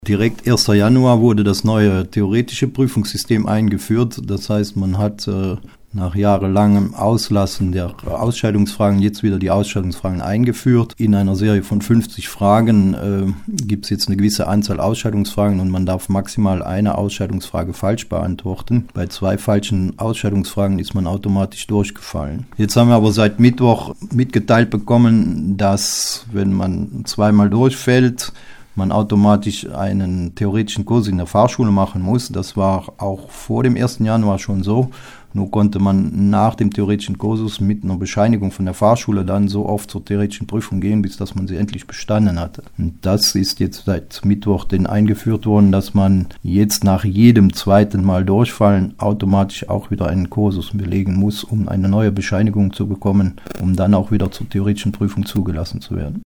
hat sich mit Fahrlehrer